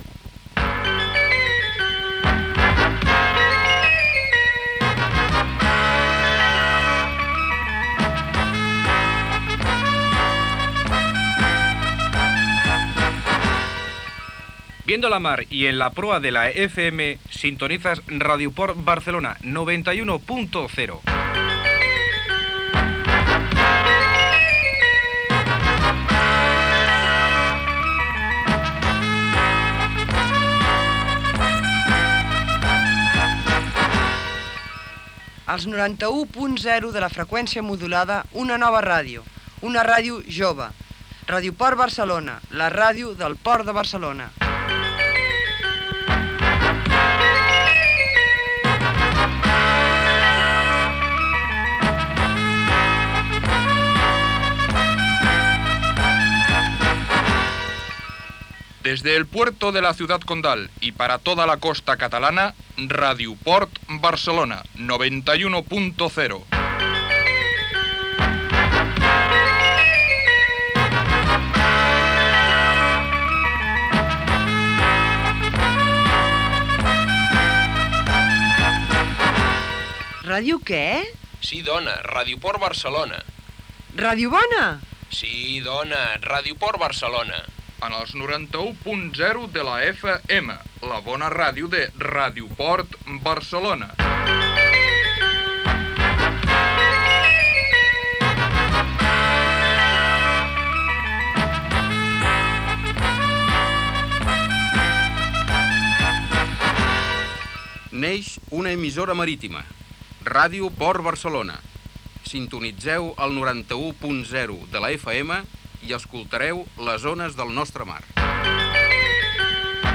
Sintonia amb identificacions i eslògans.